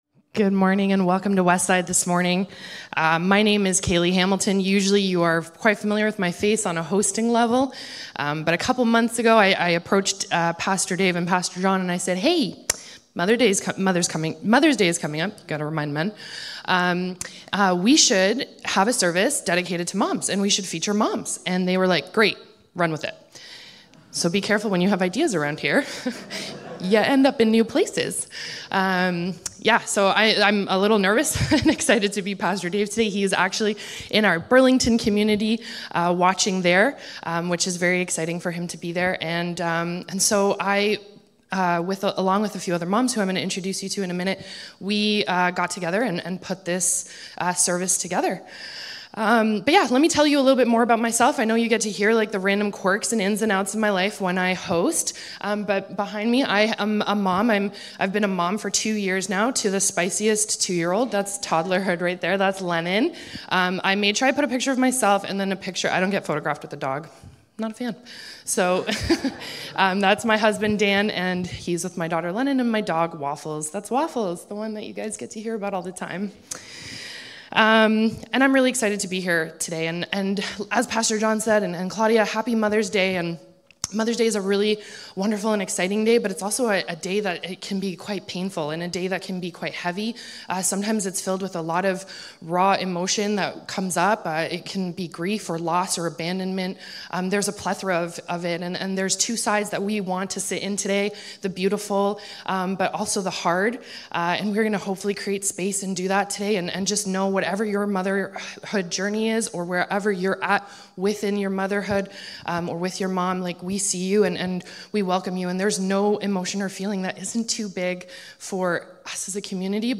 Today we have some women from our community sharing stories and insights through a special Mother's Day panel. They will discuss how support networks and shared experiences shape their journey as mothers, highlighting the vital role of community.